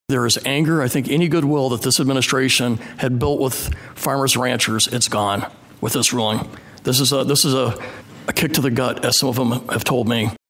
Environmental Protection Agency chief Michael Regan was called out on biofuel use, pesticide registrations, and the Biden Waters of the U.S. rule at a House Ag hearing.